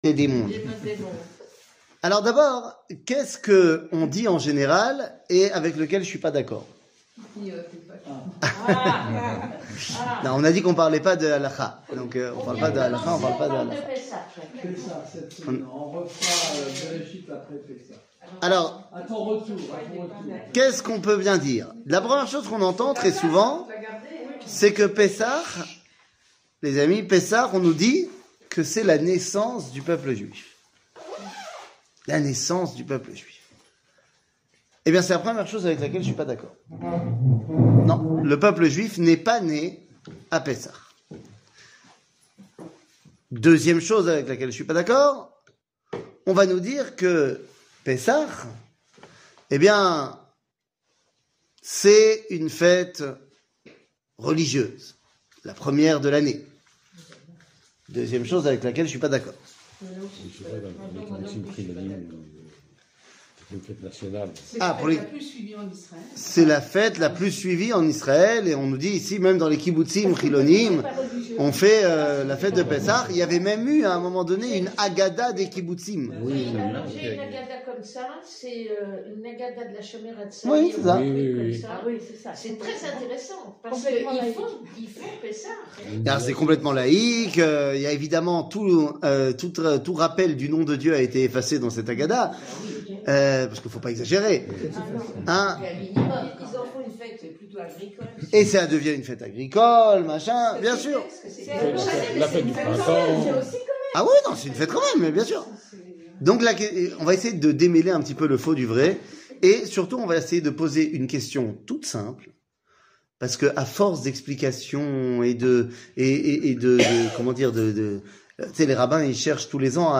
Pessah, Liberte, egalite , Nationalite 00:44:46 Pessah, Liberte, egalite , Nationalite שיעור מ 13 אפריל 2022 44MIN הורדה בקובץ אודיו MP3 (40.99 Mo) הורדה בקובץ וידאו MP4 (102.38 Mo) TAGS : שיעורים קצרים